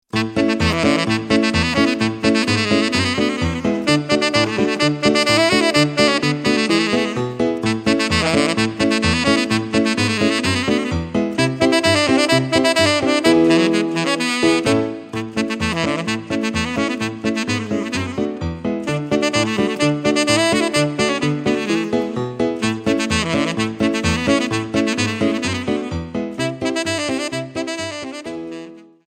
Saxophone Alto ou Tenor et Piano